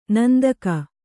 ♪ nandaka